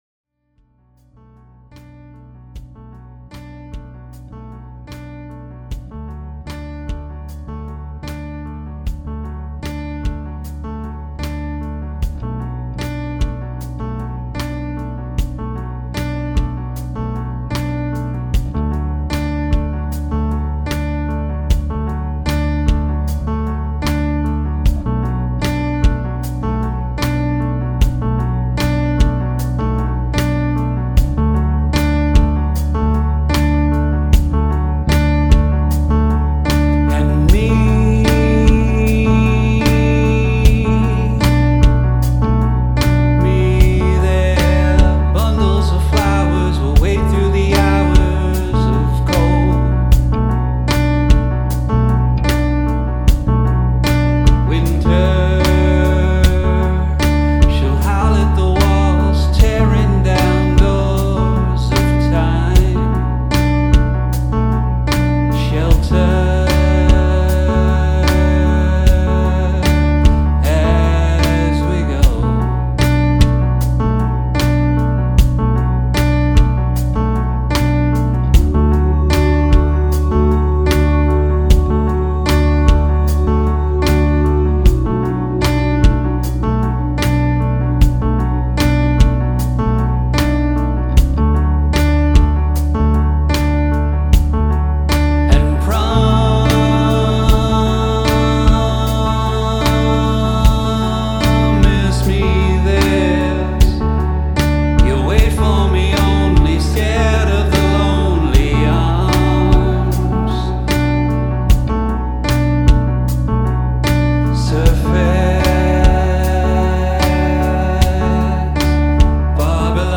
Bass, Piano, String Synth, Drum Sequencing
Vocals
in his modest home studio